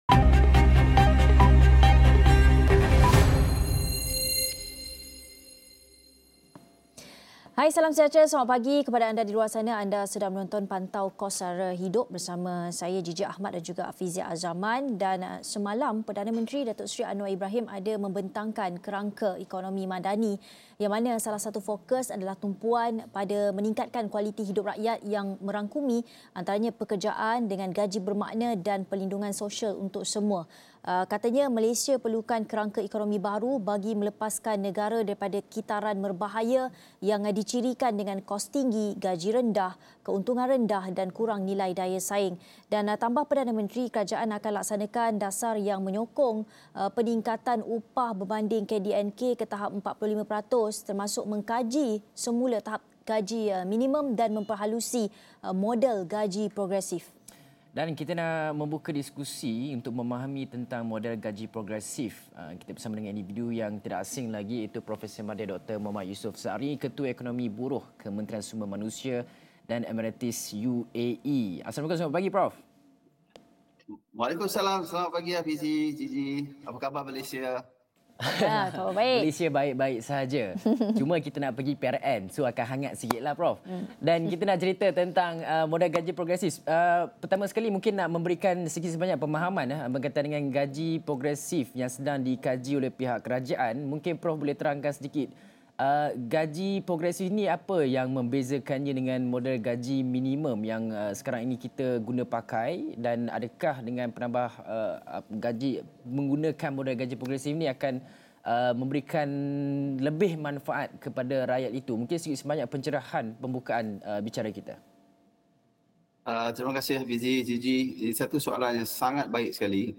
Ikuti diskusi susulan pelancaran Ekonomi Madani, berkaitan bagaimana model gaji progresif boleh berfungsi sebagai satu perlindungan sosial untuk semua